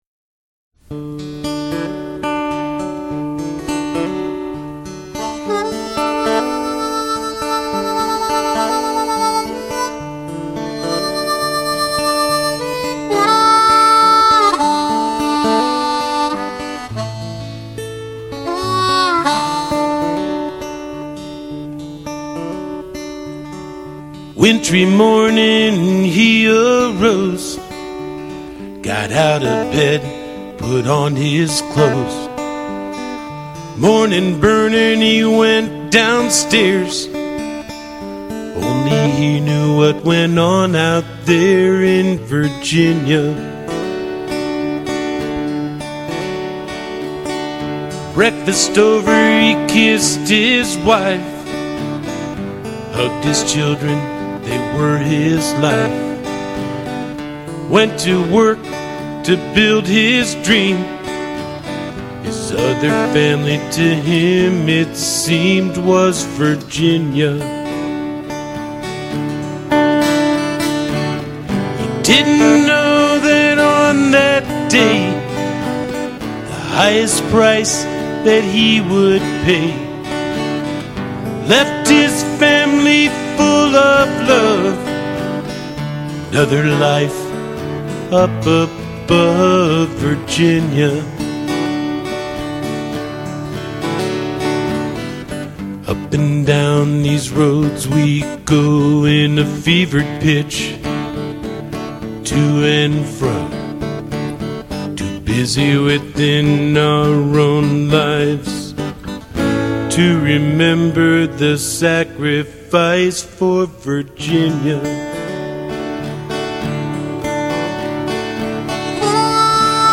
A Musical Tribute to VDOT's Fallen Workers:
Vocal, Harmonica
Guitar
Recorded and Engineered:      Tonemaster Studio, Staunton, VA